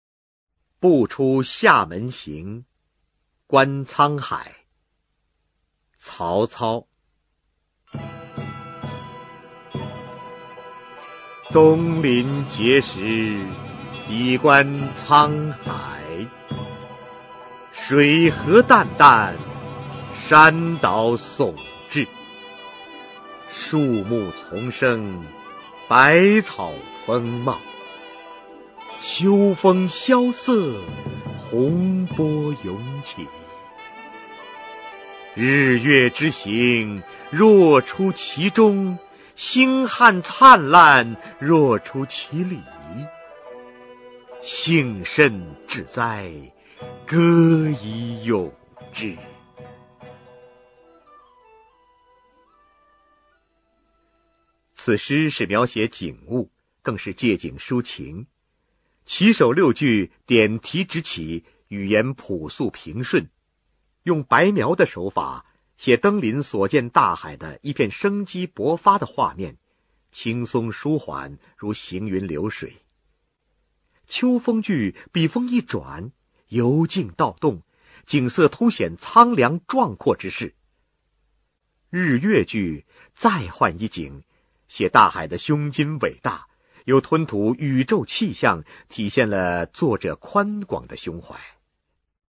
[魏晋诗词诵读]曹操-观沧海（男） 古诗朗诵